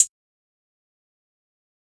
Metro Hats [Fast].wav